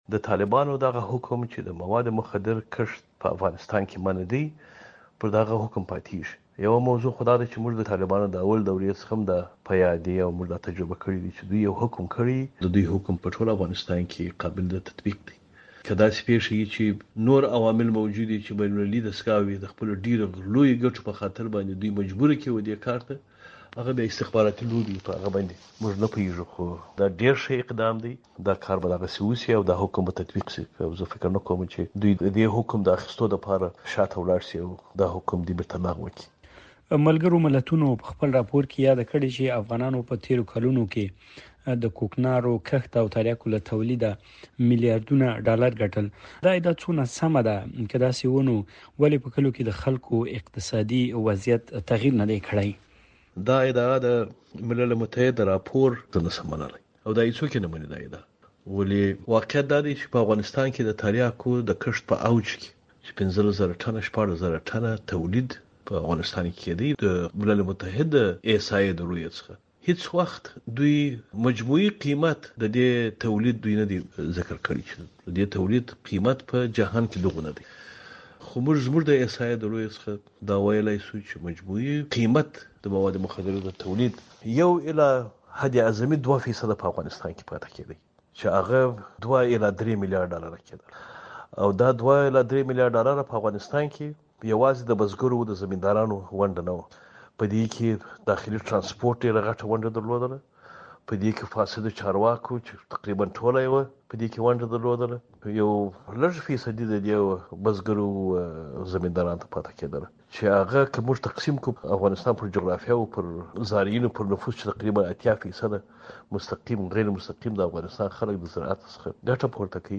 د زهرو کاروان مرکه